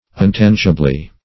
untangibly - definition of untangibly - synonyms, pronunciation, spelling from Free Dictionary Search Result for " untangibly" : The Collaborative International Dictionary of English v.0.48: Untangibly \Un*tan"gi*bly\, adv.